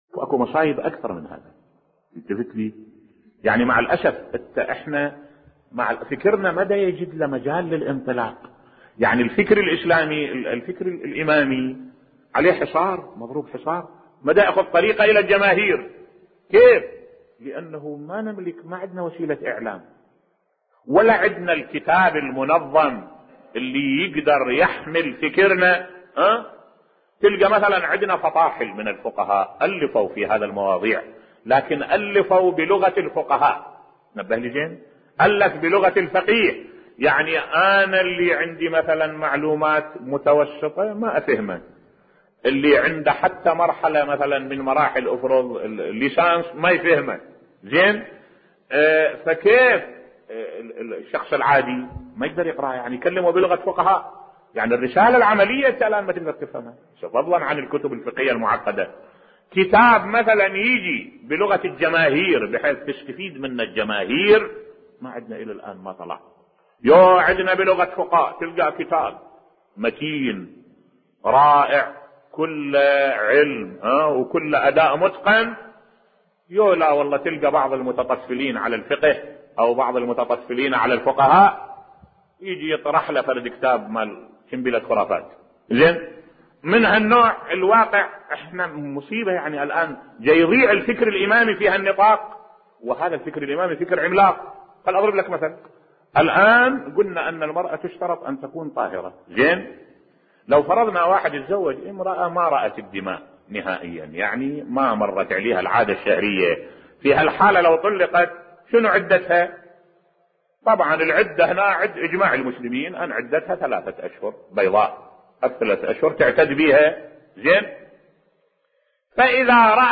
ملف صوتی فكر أهل البيت لا يهدم الأسر الاسلامية كبقية المذاهب الاسلامية ومع ذلك توضع له العراقيل بصوت الشيخ الدكتور أحمد الوائلي